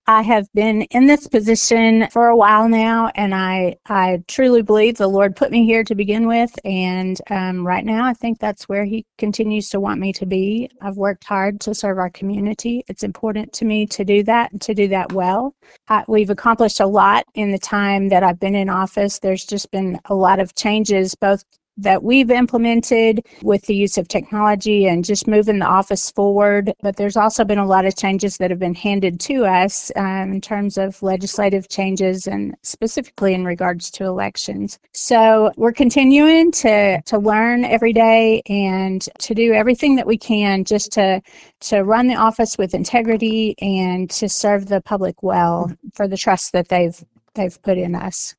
KTLO News spoke with Reese and spoke on how much the trust of the community means to her.